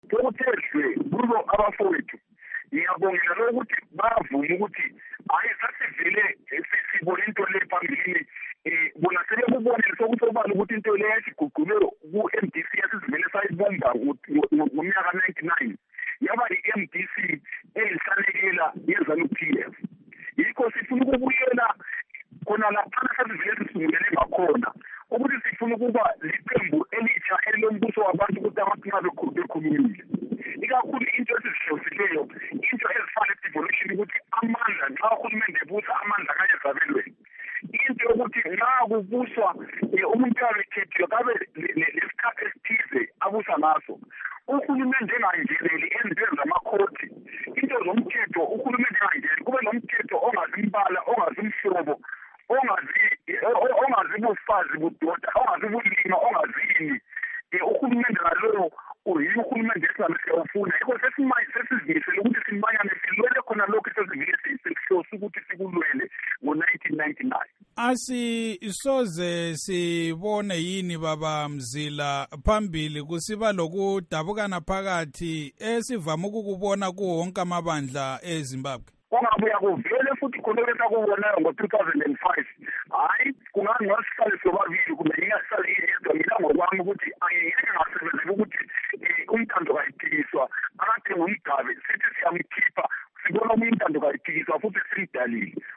Ingxoxo loMnu Moses Mzila Ndlovu